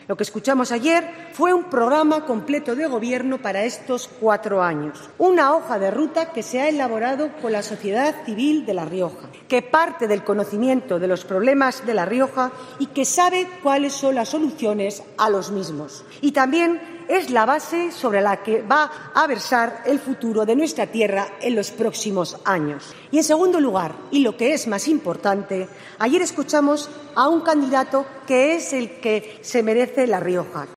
Este mediodía, en el Parlamento de La Rioja, se ha reanudado el pleno de investidura del candidato del PP a la presidencia del Gobierno de La Rioja, Gonzalo Capellán, ya con la intervención de un representante de cada grupo parlamentario.
Y ha cerrado el turno de portavoces, la del Grupo Popular, Cristina Maiso.